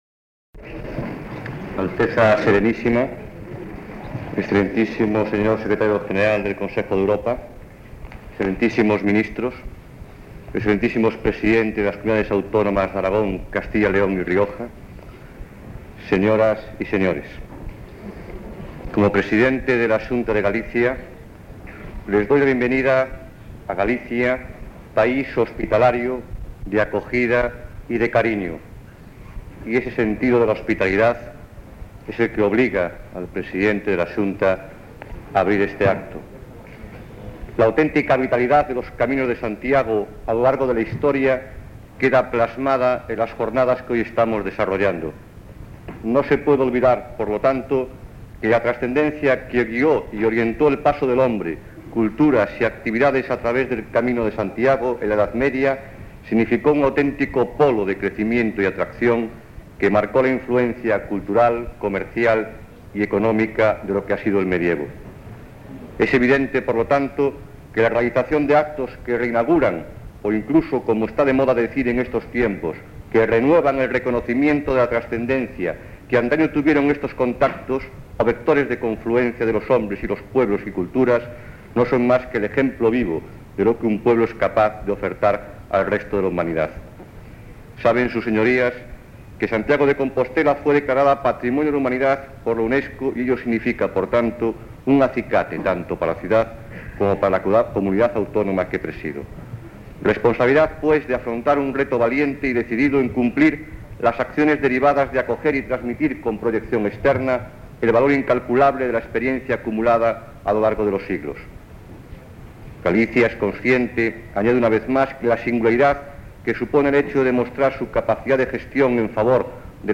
Discurso de D. Fernando González Laxe, presidente de la Xunta de Galicia
Acto de proclamación del Camino de Santiago como Itinerario Cultural Europeo. 1987